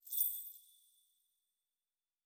03_鬼差脚步_1.wav